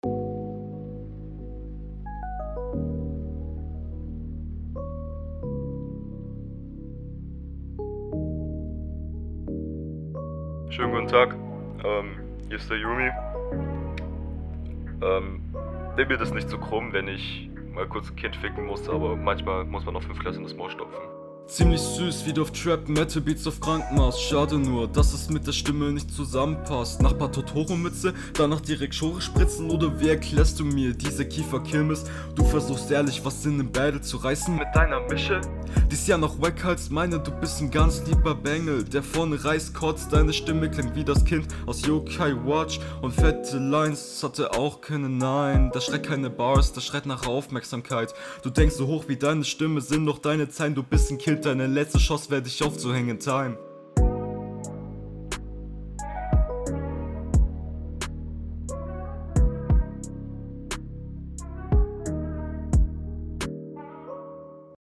Flow: Flow ist etwas langweilig.
Nicht immer ganz tight.
entspannter beat. vielleicht schon n ticken zu entspannt. flowst schon ganz stabil drüber, aber wirkt …